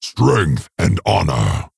StrengthAndHonour_VO_801_Maghar_Orc_Military_Guard_34_M.ogg